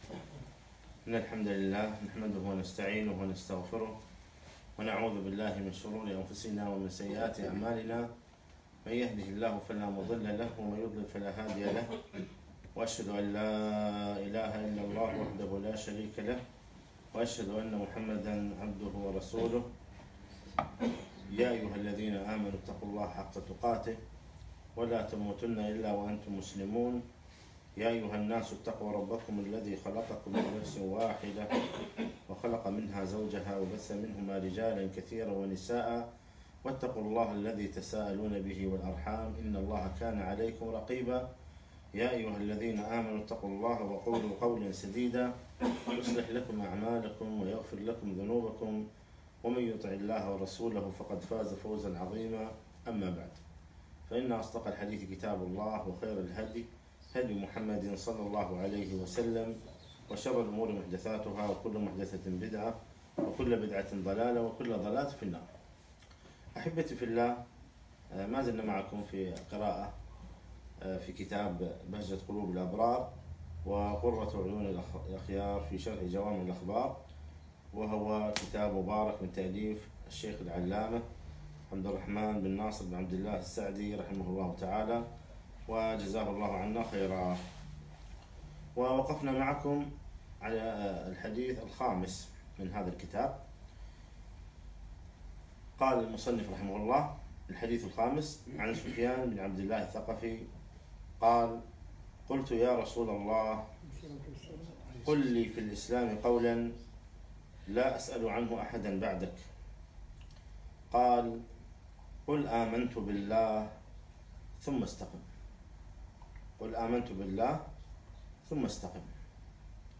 محاضرة - قل امنت بالله ثم استقم